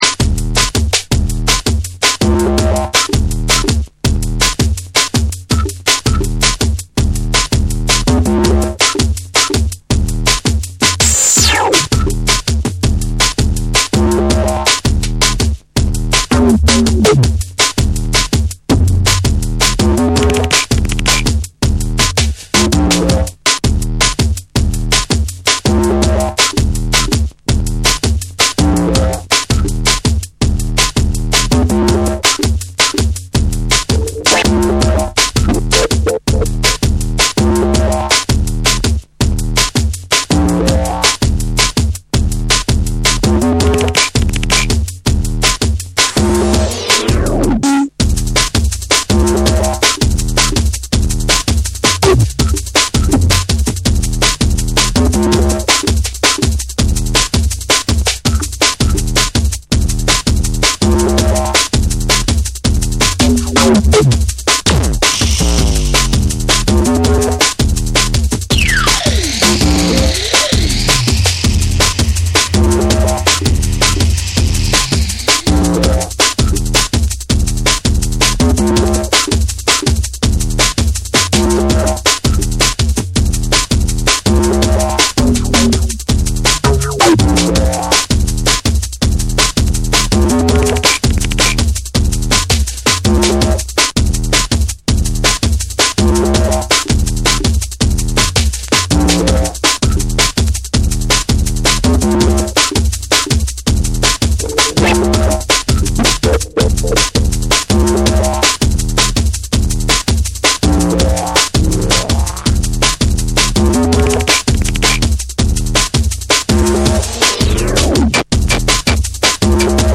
ジャズやソウルの感覚を取り込んだベースラインと、しなやかで躍動感のあるビートが融合したドラムンベース
JUNGLE & DRUM'N BASS